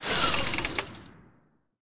lacunaeSpindown.ogg